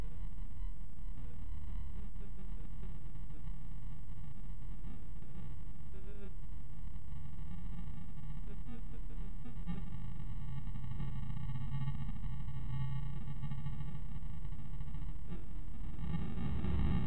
TLV320AIC1110: Mettalic sound over SAI output.
As mentioned in above dia I am sending data over USB, this sound data has an metallic sound.
I2S standard mode
stereo mode